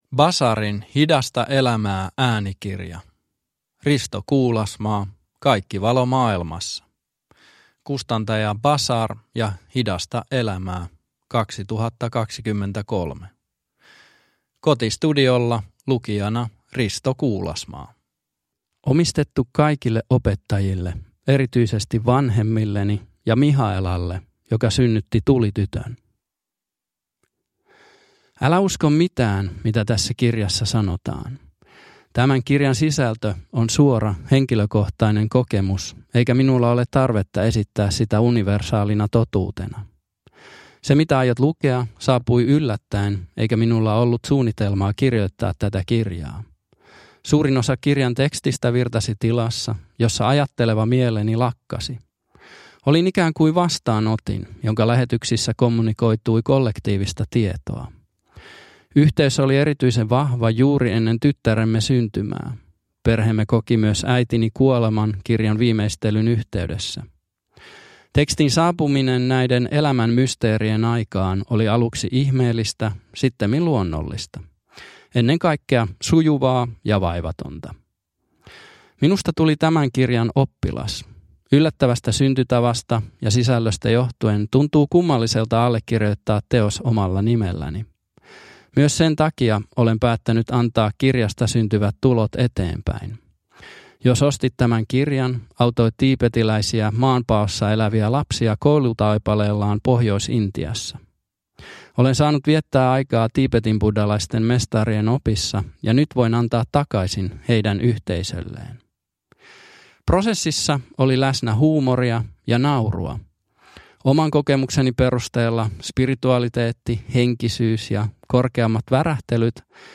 Kaikki valo maailmassa – Ljudbok – Laddas ner